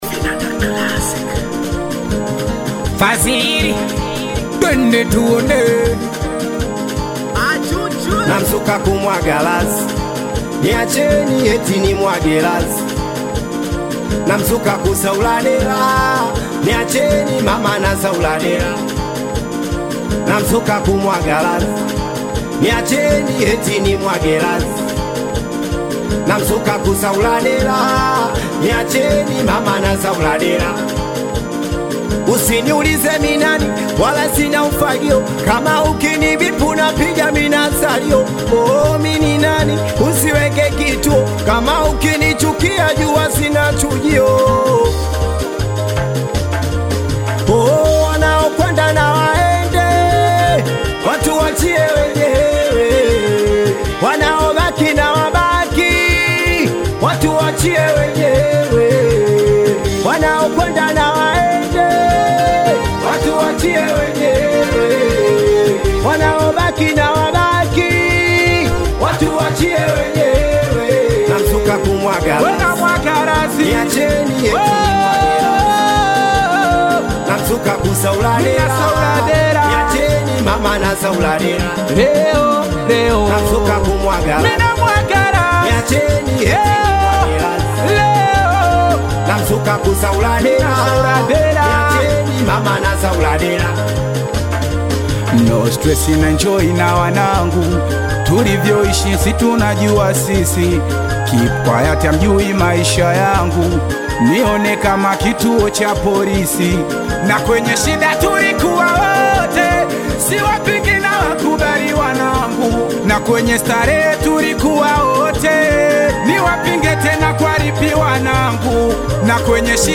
Singeli music track
Tanzanian Bongo Flava artists